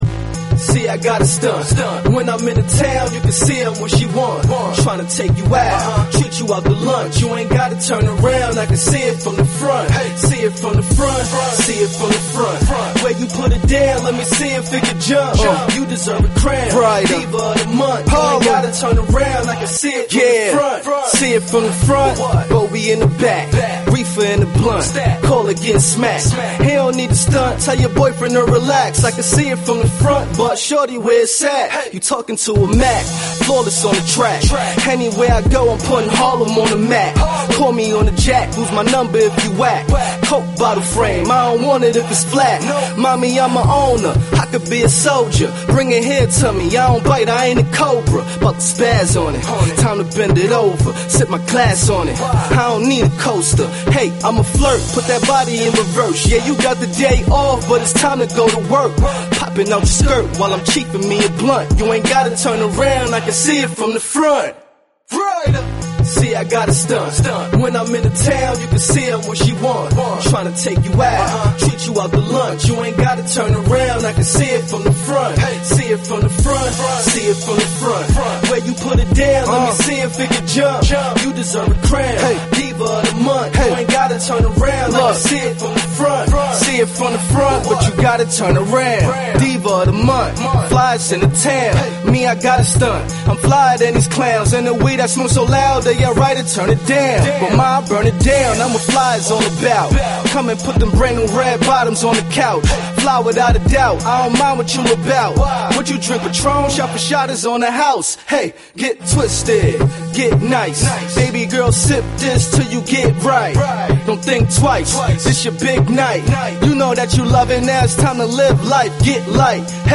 During each broadcast, there will be in-depth guest interviews discussing the problems and advantages business owners face. Topics will include sales and marketing, branding, interviewing, and much more.
In addition to daily guests, TE spins the hottest indie musical artists and laughs along to the funniest comedians every day.